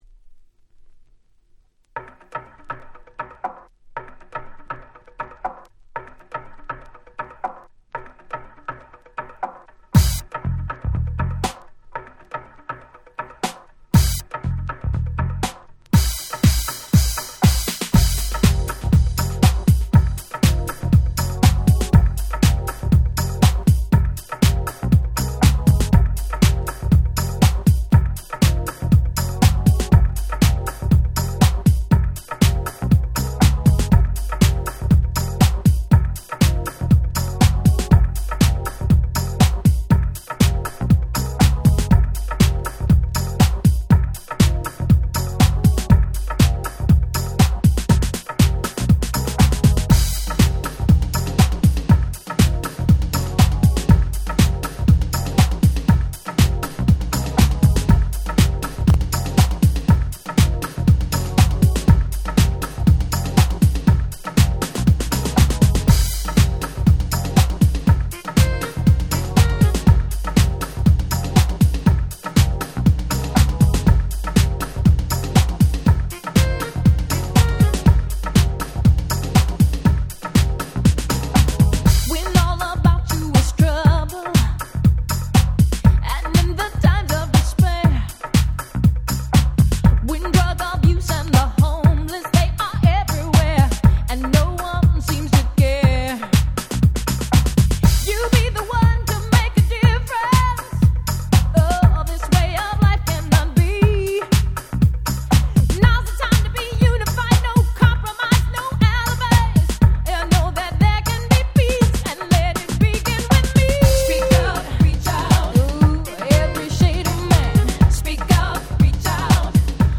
95' Very Nice R&B !!